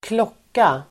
Uttal: [²kl'åk:a]